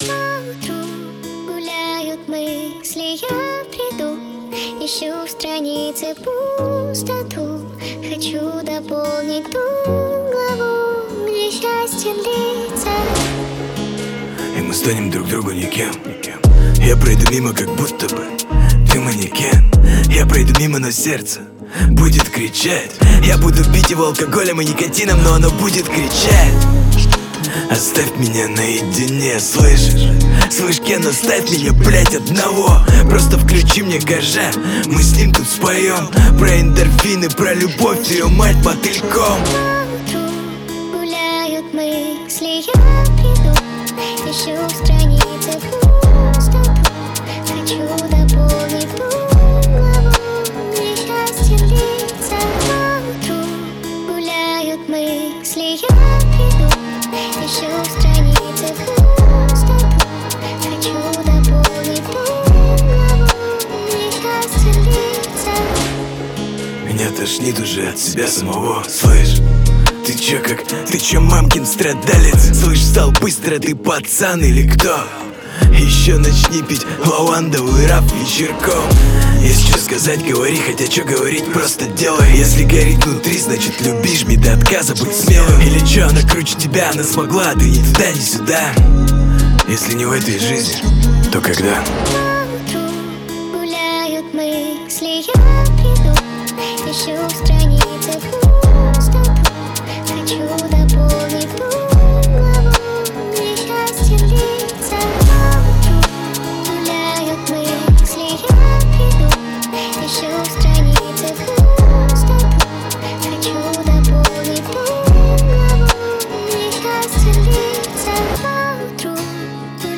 Категория: Рэп